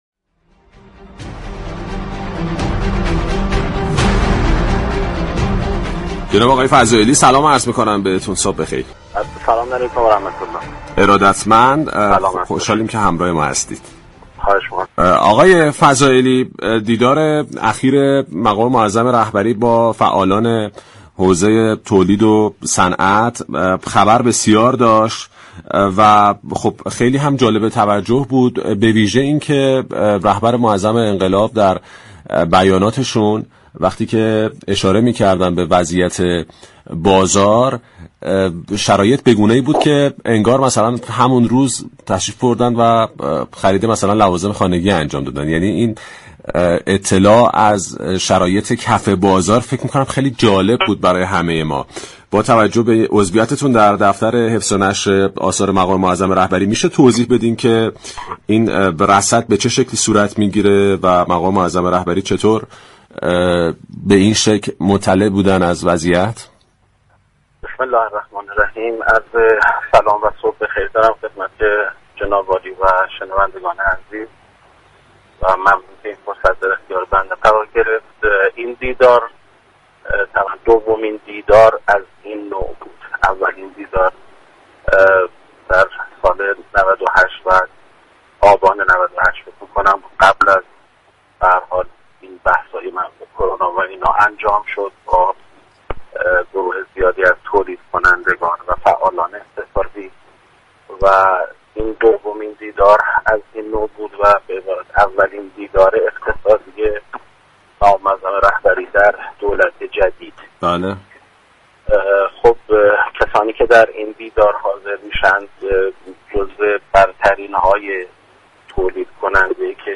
در گفتگو با پارك شهر رادیو تهران